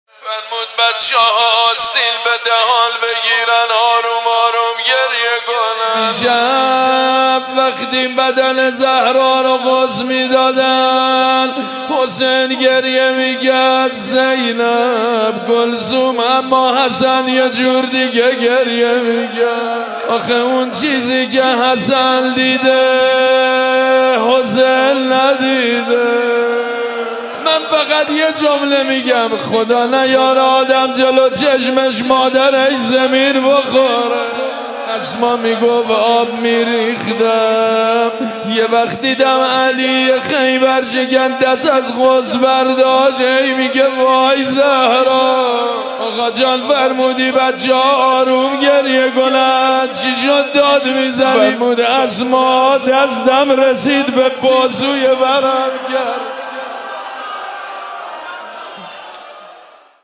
دفتر آیت الله علوی بروجردی | فاطمیه 1441